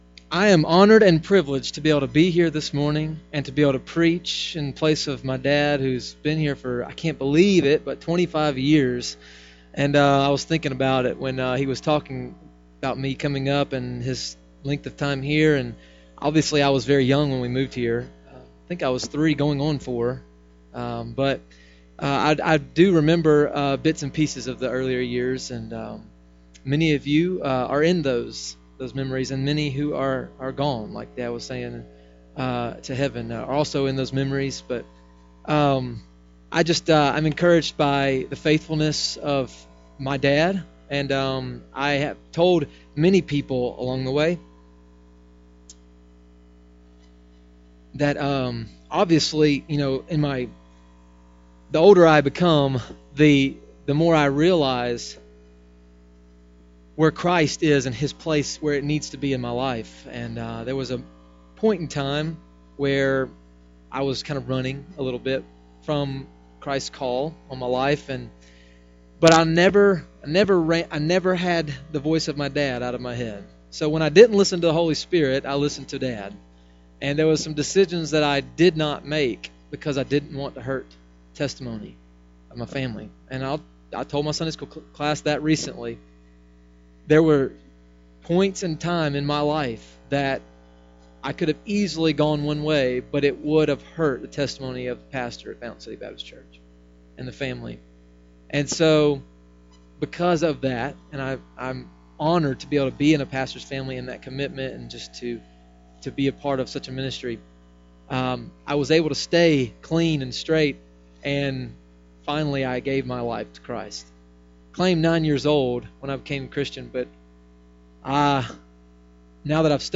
Conquering Temptation - October 19th, 2014 - AM Service - Fountain City Baptist Church